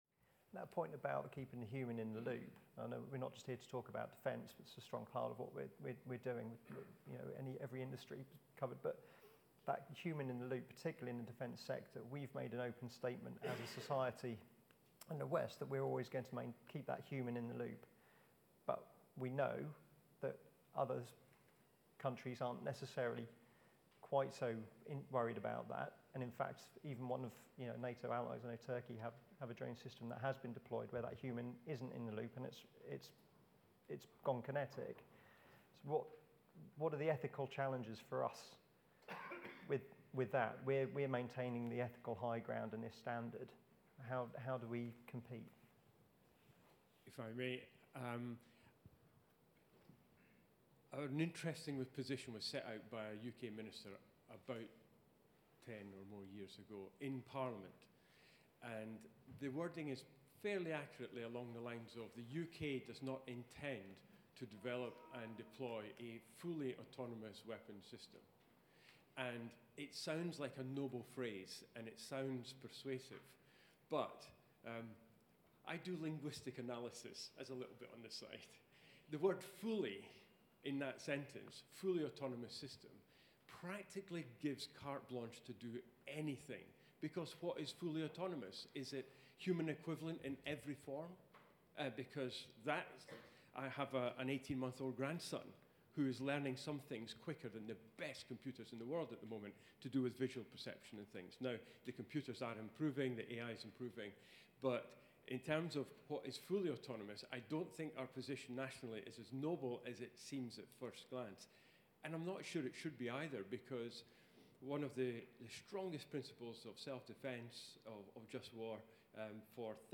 Nearly 200 guests from across the defence, law enforcement, and national security sectors attended the insightful event, which was hosted at the BattleLab, Dorset Innovation Park.